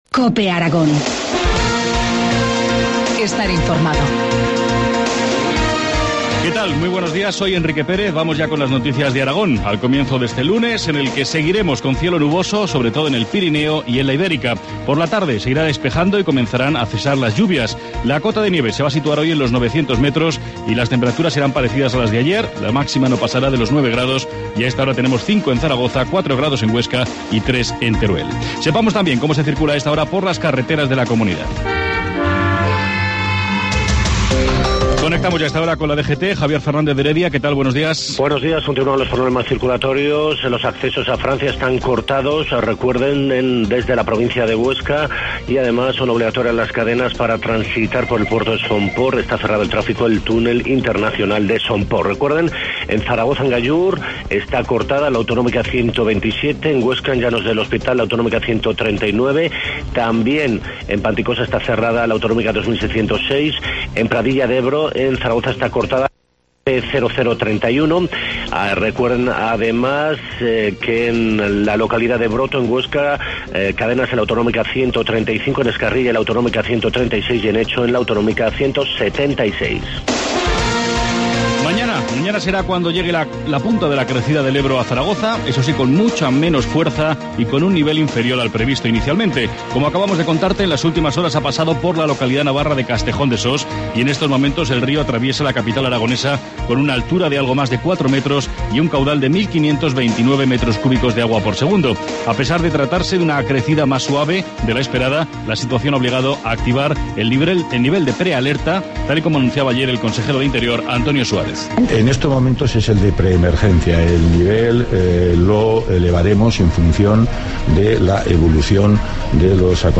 Informativo matinal, lunes 21 de enero, 7.25 horas